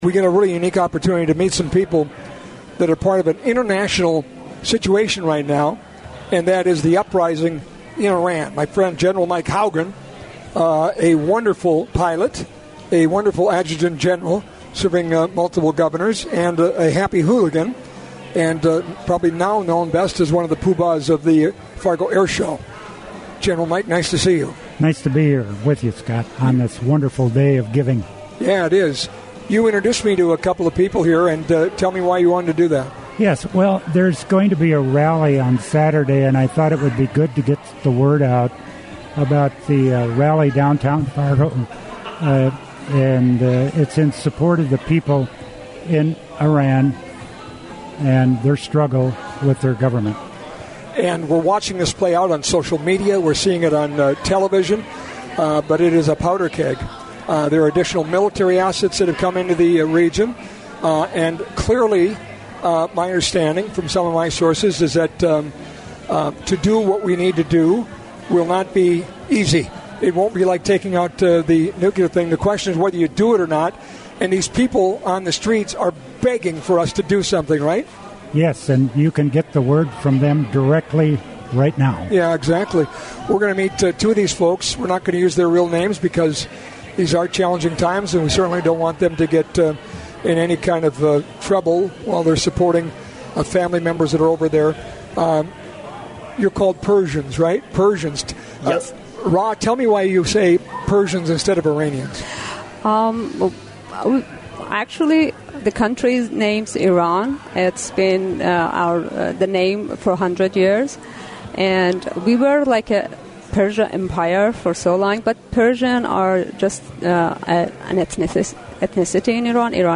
iran-conversation.mp3